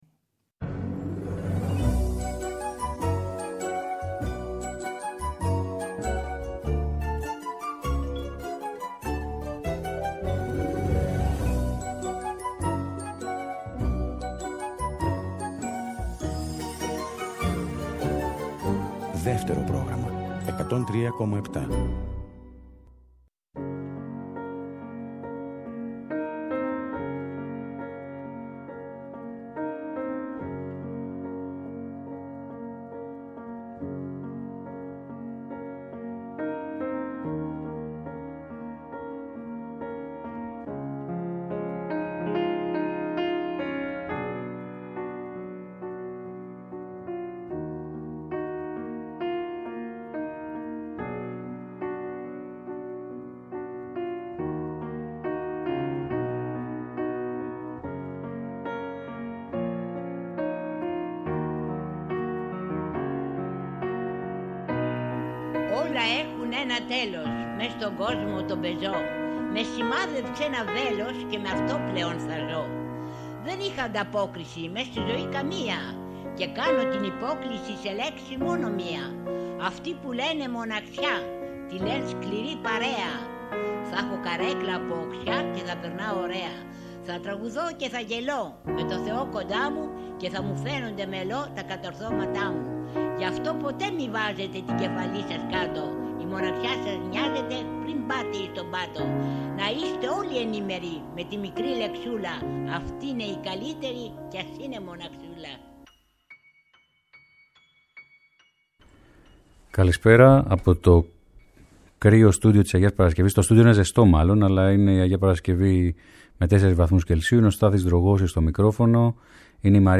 Αγκαλιές που δείχνουν το τέλος ή σφραγίζουν την αρχή. Μια αγκαλιά τραγούδια ελληνικά στην “Αντέλμα”, στις 17.00 το απόγευμα ακριβώς!